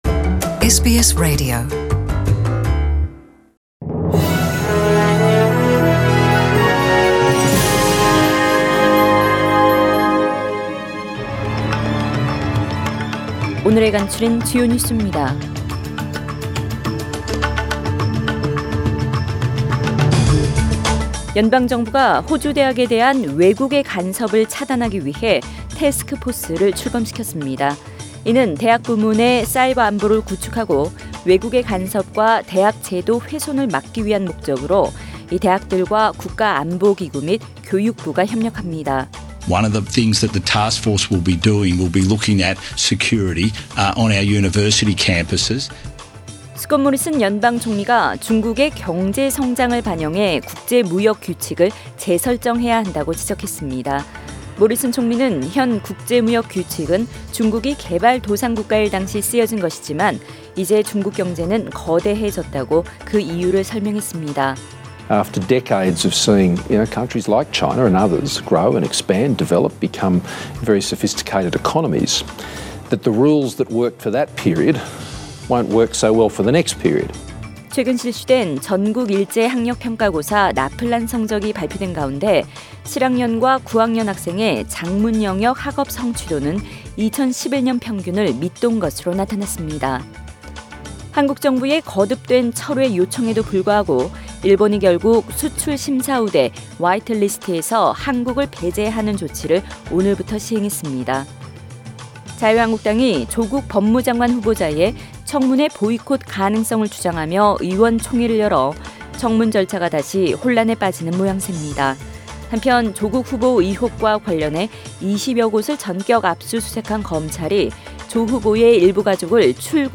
2019년 8월 28일 수요일 저녁의 SBS Radio 한국어 뉴스 간추린 주요 소식을 팟 캐스트를 통해 접하시기 바랍니다.